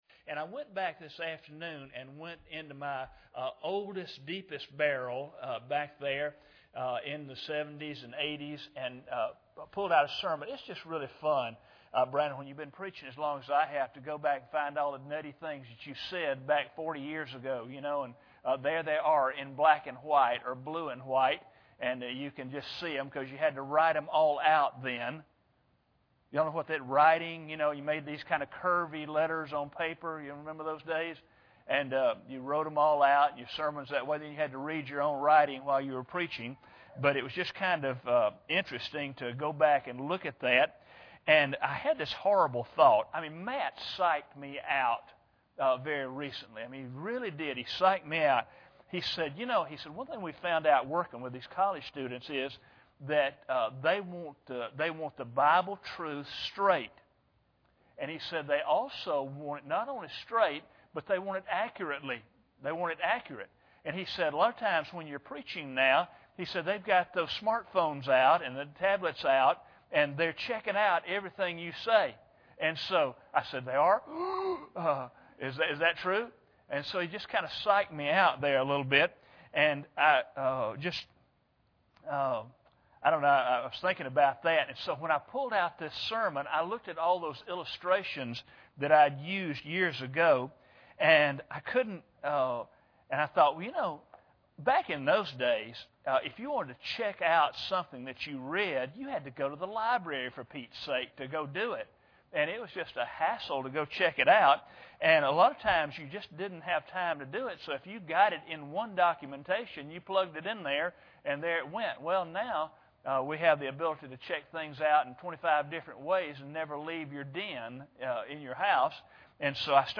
Psalm 138:1-2 Service Type: Sunday Evening Bible Text